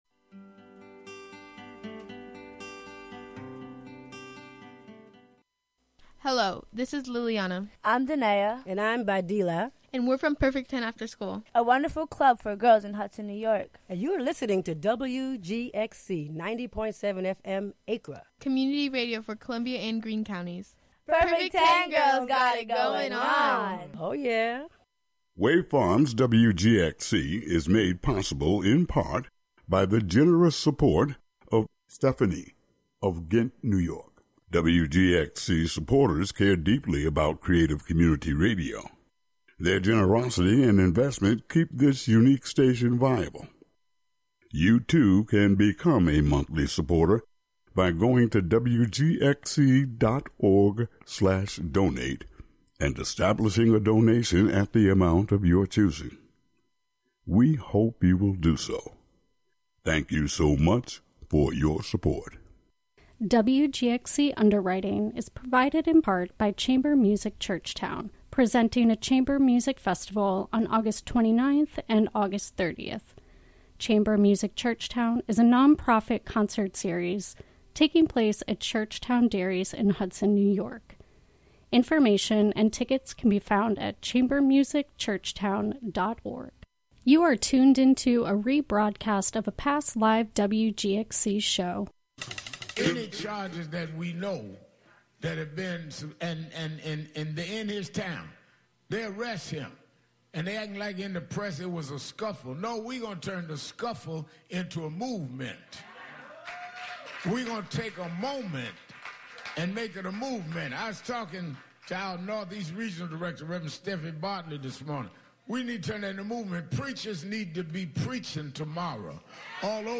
11am Live from Brooklyn, New York